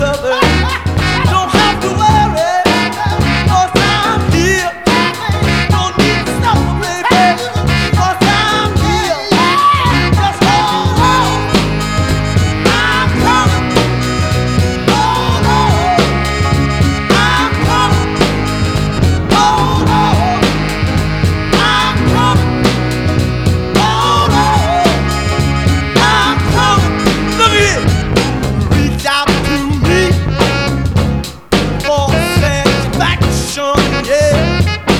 Жанр: R&b / Рок / Соул